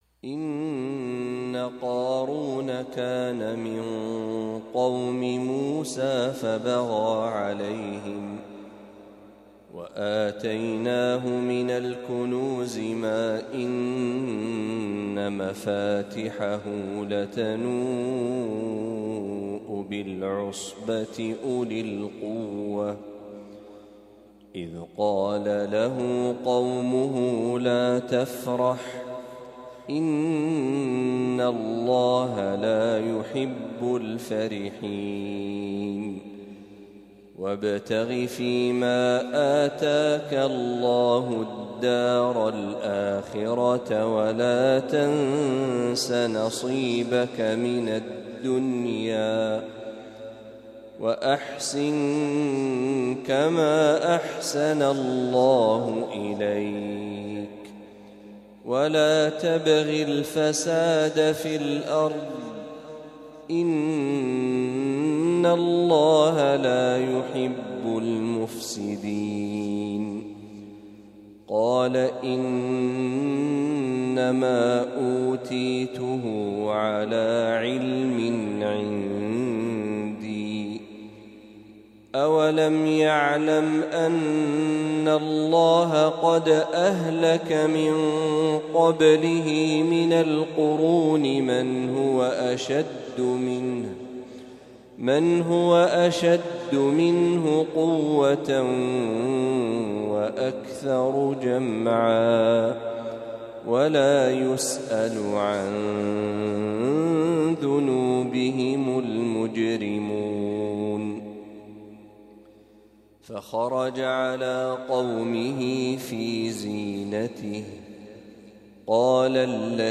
تلاوة لخواتيم سورة القصص